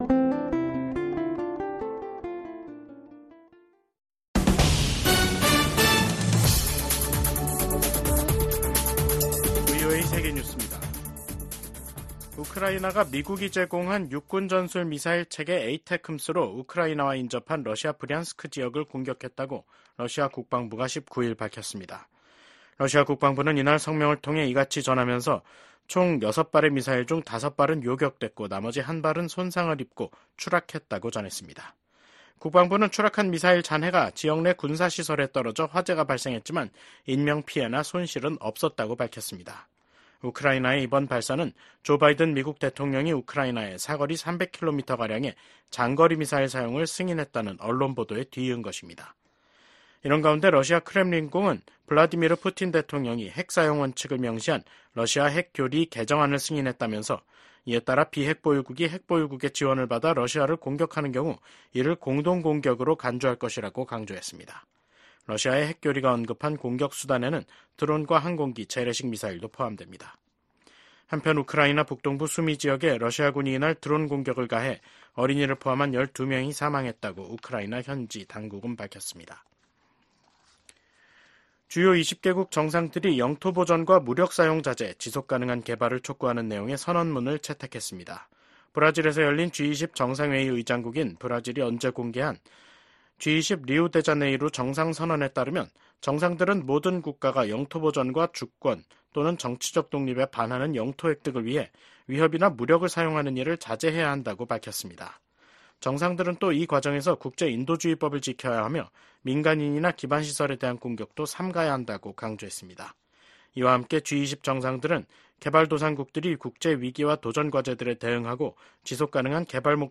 VOA 한국어 간판 뉴스 프로그램 '뉴스 투데이', 2024년 11월 19일 3부 방송입니다. 미국은 러시아가 북한군을 우크라이나 전쟁에 투입해 분쟁을 고조시키고 있다며, 북한군의 추가 파병을 차단하기 위해 중국과 직접 소통하고 있다면서, 단호한 대응 의지를 확인했습니다. 우크라이나 전쟁 발발 1천일을 맞아 열린 유엔 안보리 회의에서 북한군의 러시아 파병과 두 나라 간 군사 협력에 대한 강한 비판이 쏟아졌습니다.